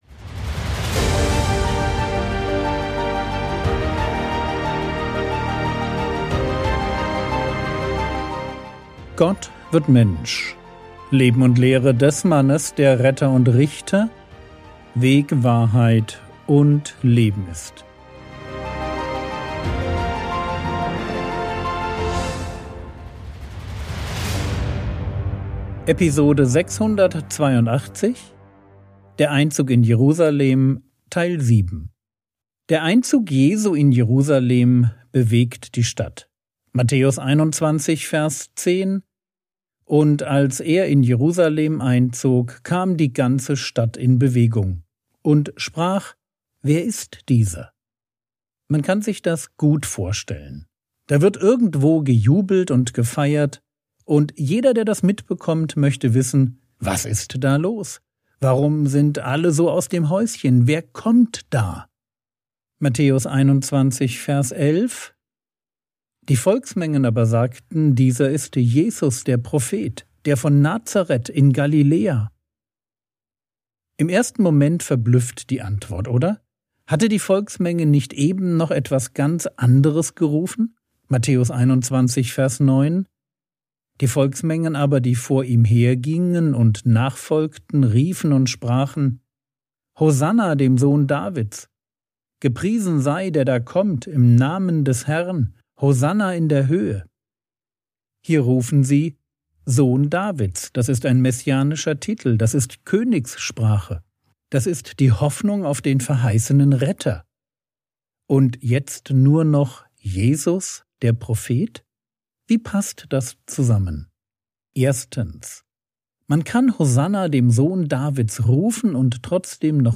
Episode 682 | Jesu Leben und Lehre ~ Frogwords Mini-Predigt Podcast